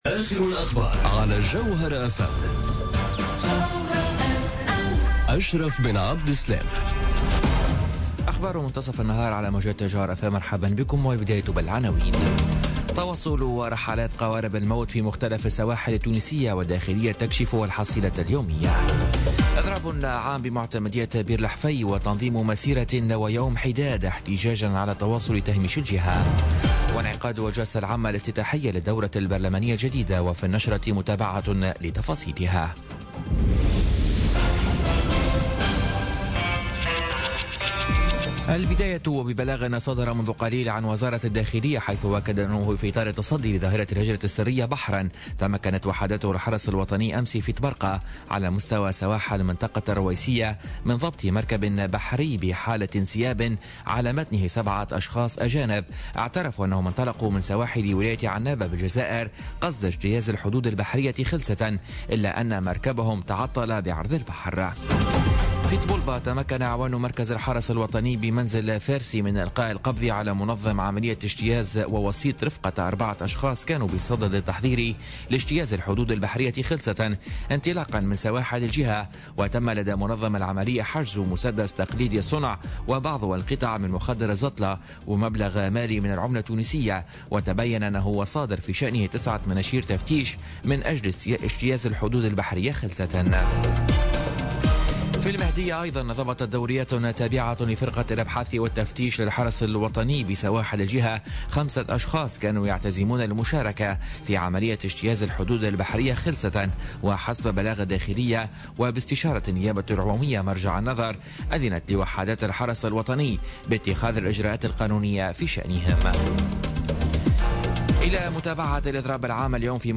نشرة أخبار منتصف النهار ليوم الإربعاء 18 أكتوبر 2017